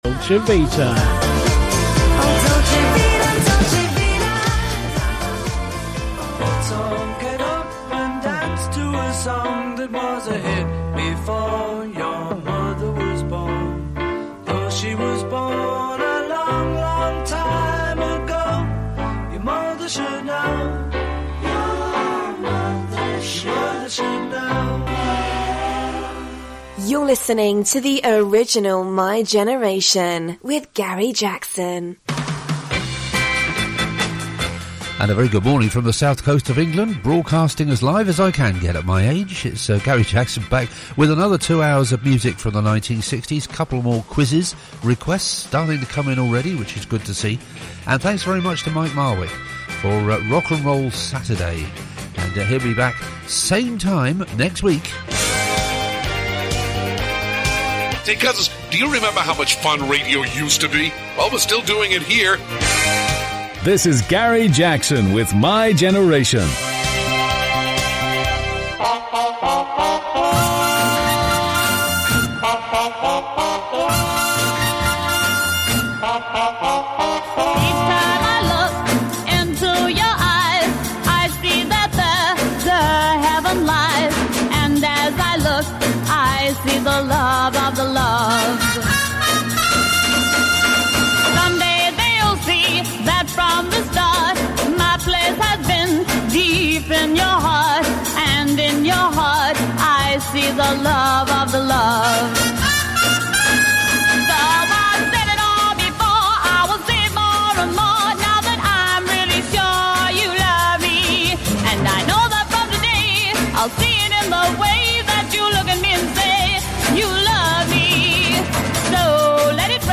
An Offshore Radio Day Special featuring clips and songs from the Pirate Radio Era
2 Hours of Hits and Rarities, all from the 1960s